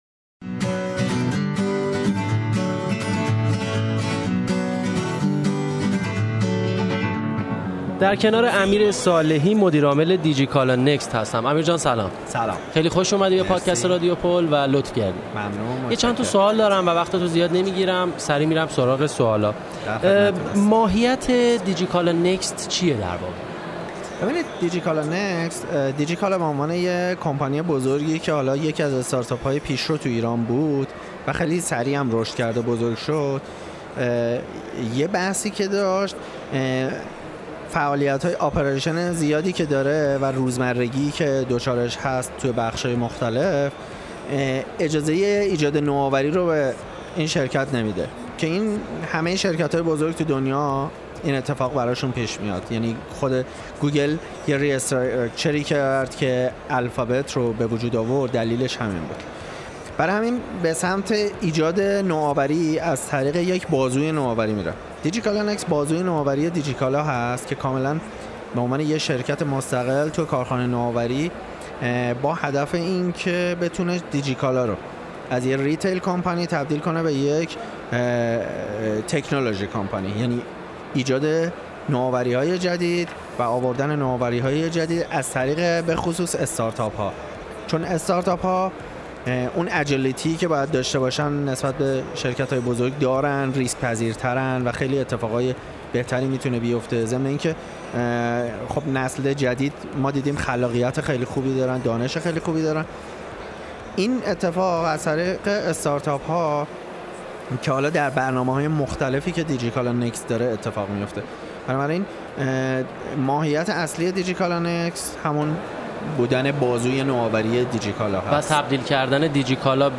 رادیو پل هم در ادامه پادکست‌های اختصاصی الکامپ و با هدف انتقال تجربه به مخاطبانی که امکان حضور در الکامپ را نداشتند یا امکان حضور در همه کارگاه‌ها برایشان فراهم نبود، به گفتگو با مدیران دیگر مجموعه‌های دیجی‌کالا پرداخت.